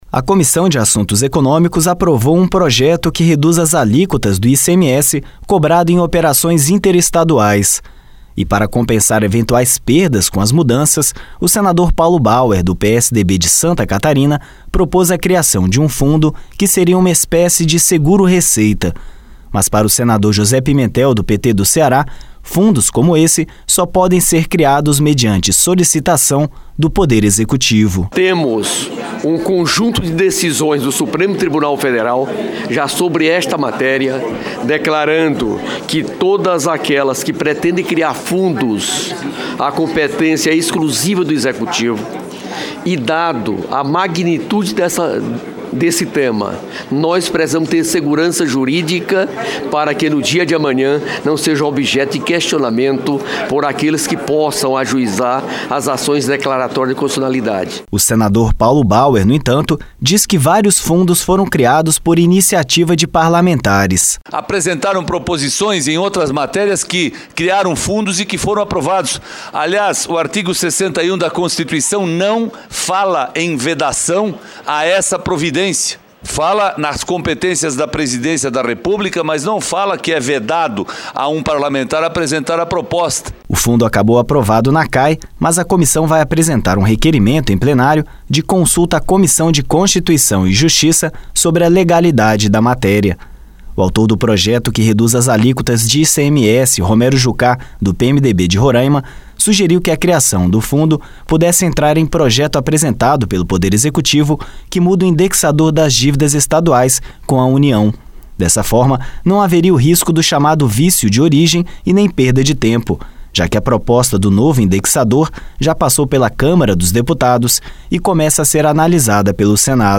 (REPÓRTER): O senador Paulo Bauer, no entanto, diz que vários fundos foram criados por iniciativa de parlamentares.